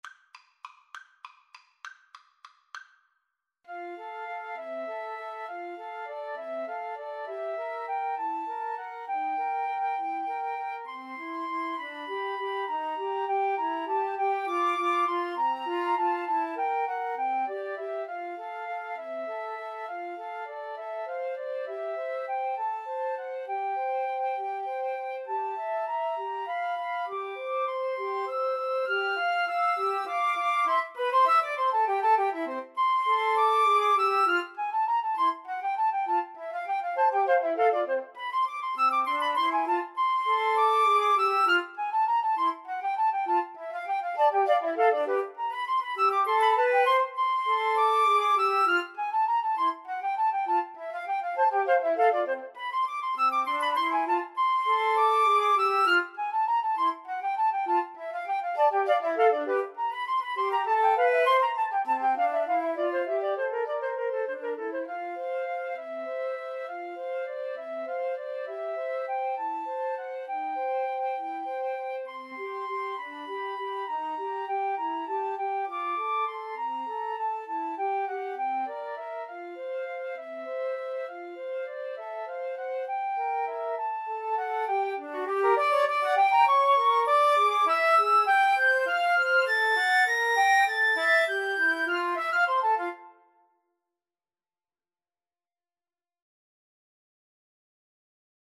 Free Sheet music for Flute Trio
F major (Sounding Pitch) (View more F major Music for Flute Trio )
Classical (View more Classical Flute Trio Music)